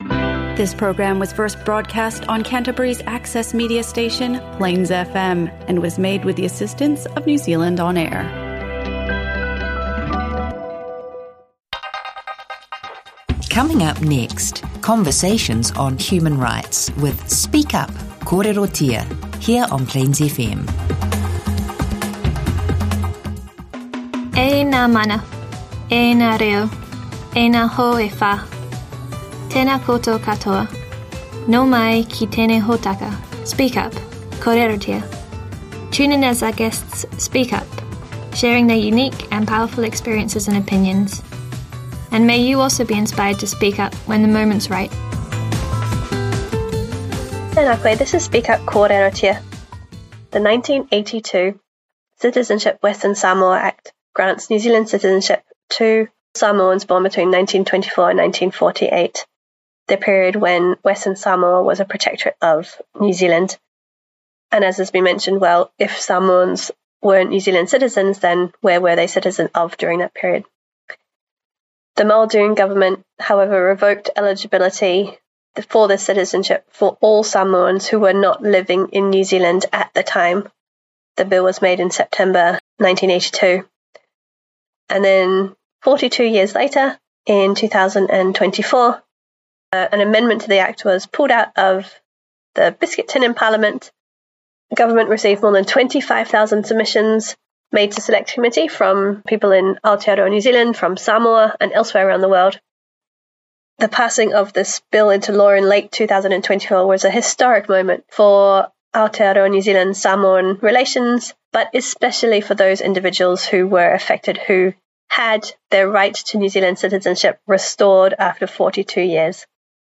Christchurch City Libraries blog hosts a series of regular podcasts from specialist human rights radio show Speak up - Kōrerotia.